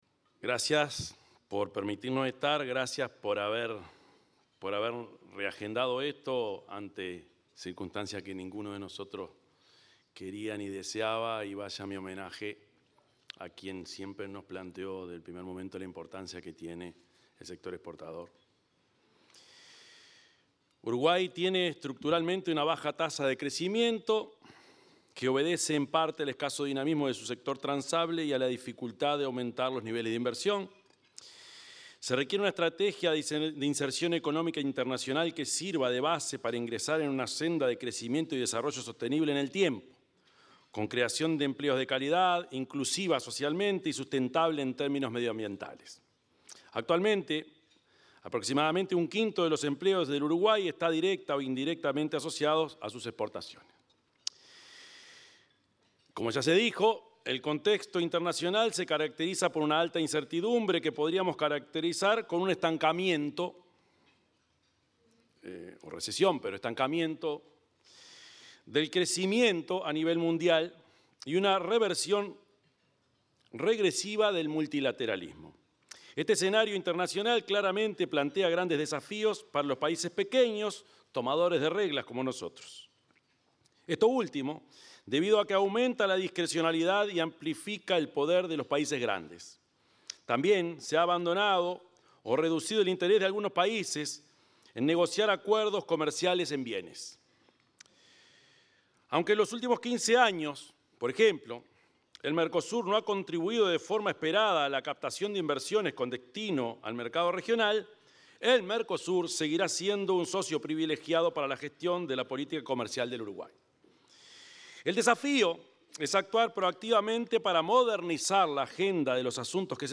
El presidente de la República, profesor Yamandú Orsi, disertó durante la ceremonia conmemorativa del Día de la Exportación.